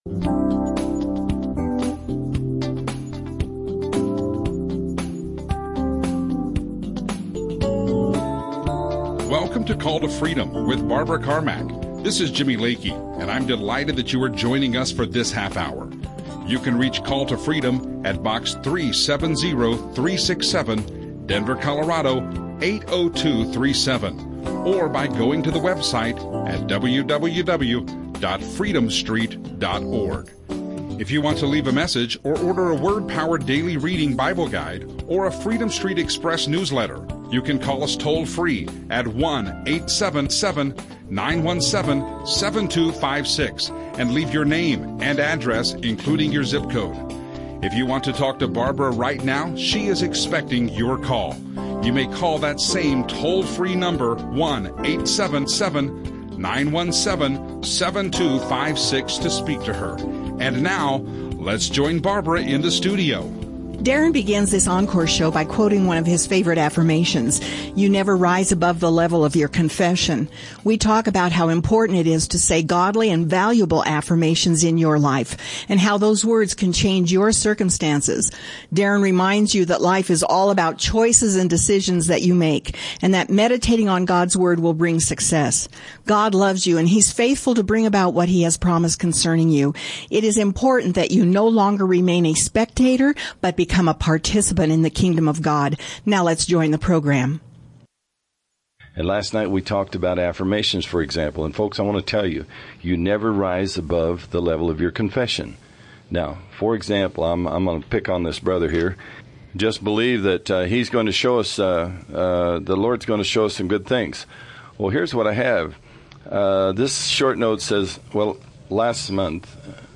Bible teaching Christian radio